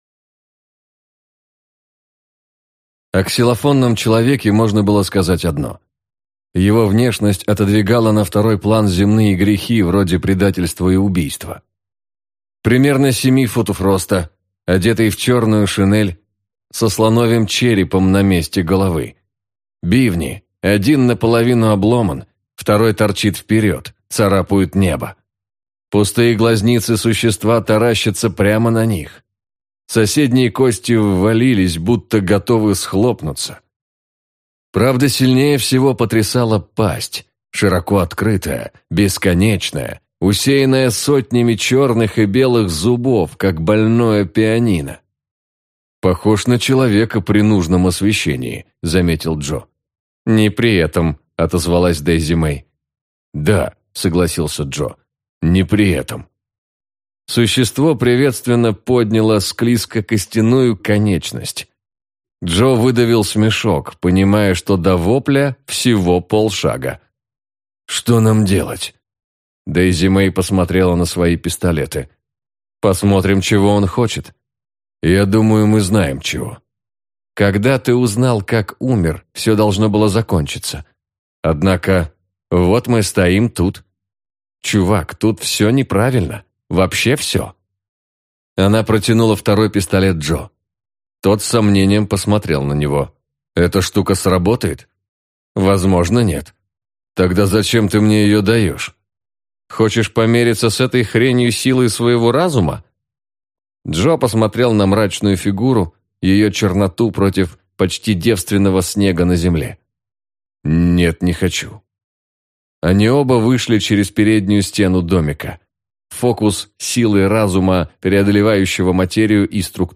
Аудиокнига Отряд мертвых | Библиотека аудиокниг